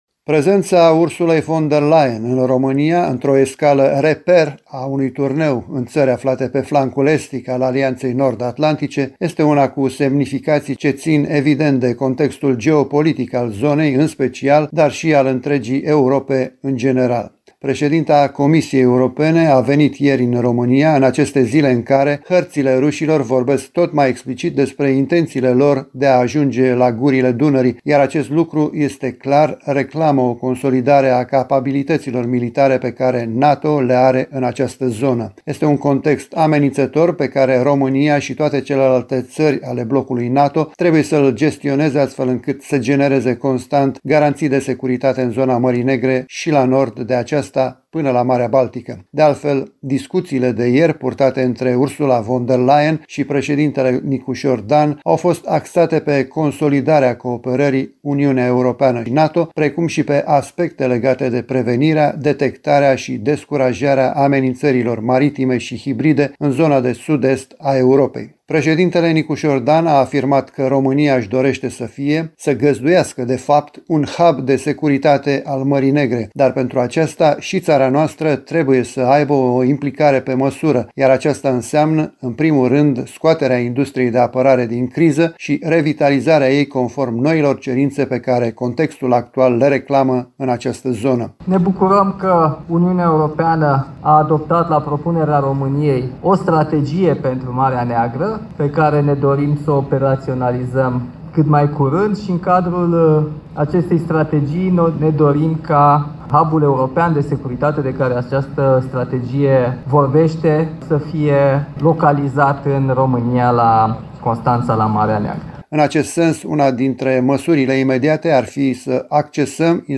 Vizita președintei CE și a liderului de la Cotroceni la Baza Aeriană de la Mihail Kogălniceanu și în Portul Militar Constanța.